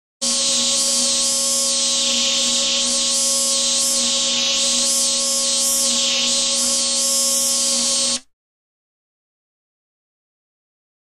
Buzz 1; Multi-pitched, Oscillating, Processed Buzz.